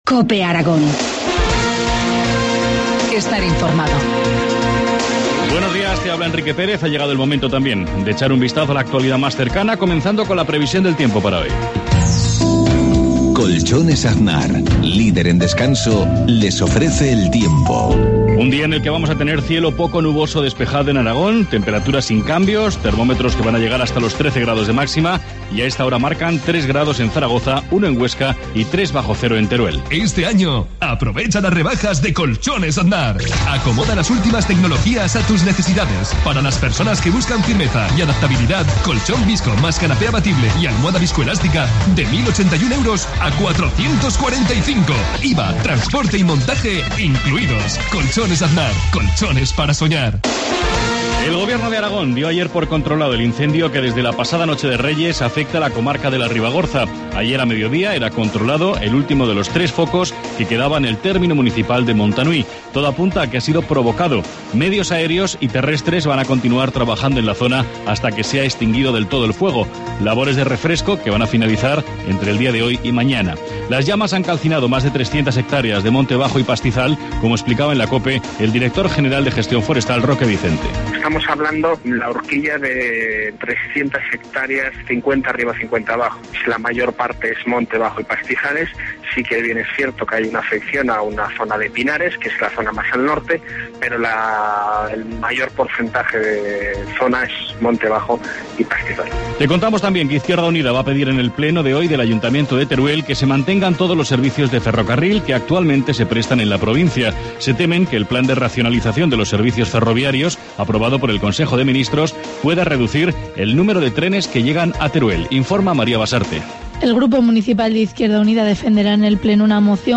Informativo matinal, martes 8 de enero, 7.53 horas